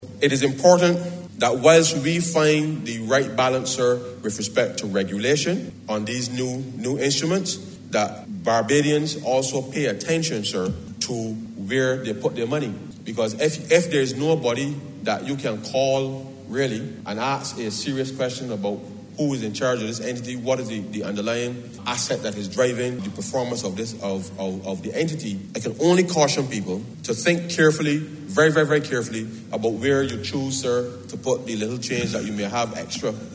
Minister in the Ministry of Finance Ryan Straughn, as he introduced in the House of Assembly, the Financial Services Commission Amendment Bill cited the collapse of regional insurance conglomerate Clico where investors lost millions of dollars.